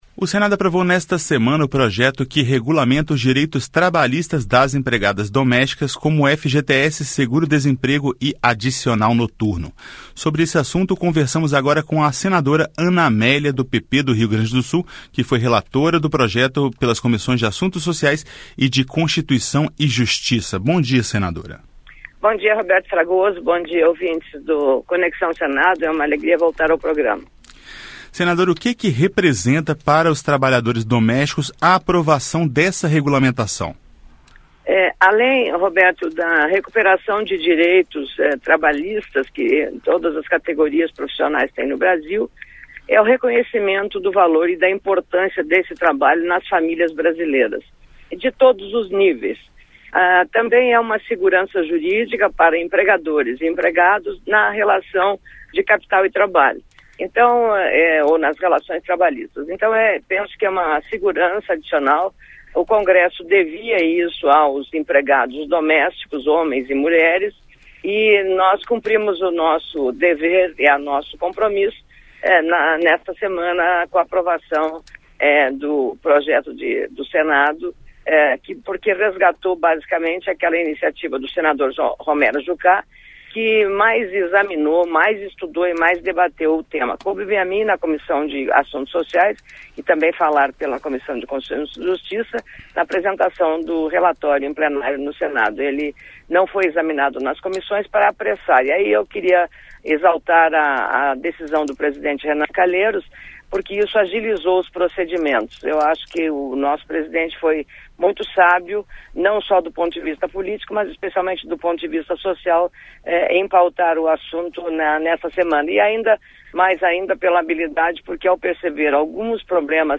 Entrevista com a senadora Ana Amélia (PP-RS), relatora do projeto nas comissões de Assuntos Sociais e de Constituição e Justiça.